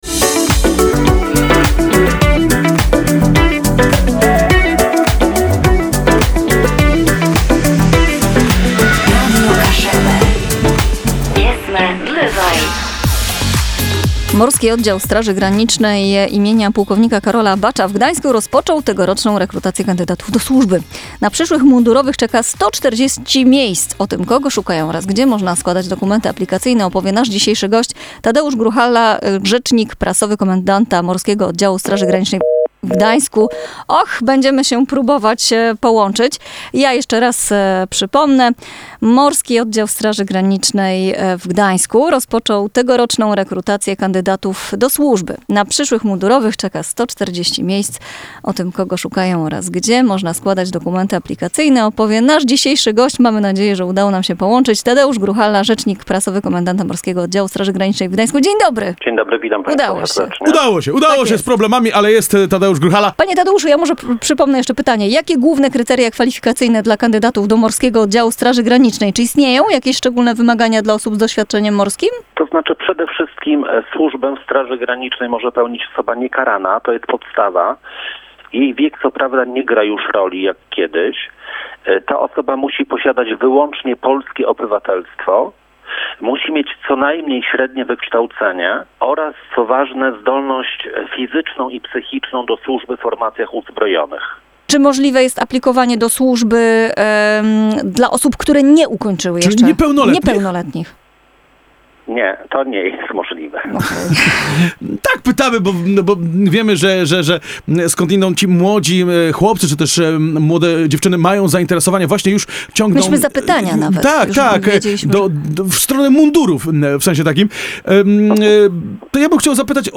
rozmowaMOSG.mp3